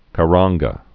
(kə-ränggə)